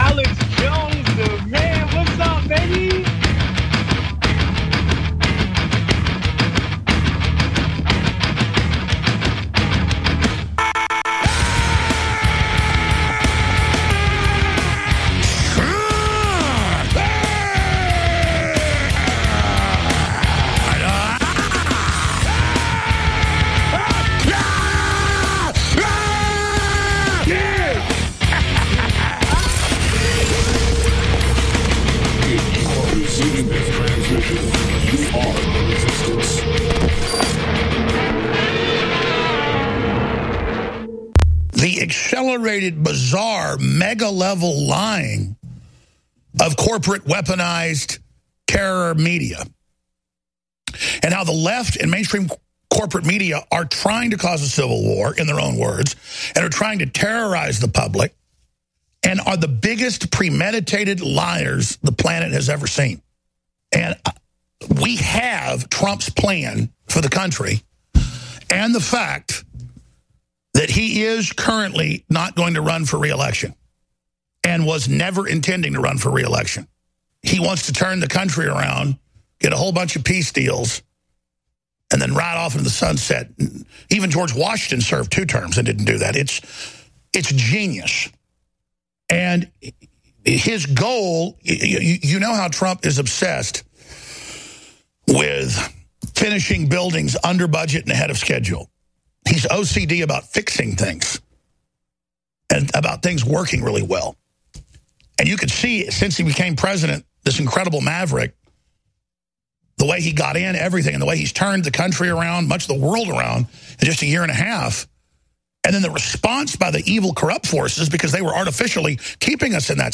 Call-In Show. Today’s show covers multiple issues important to our nation’s finest.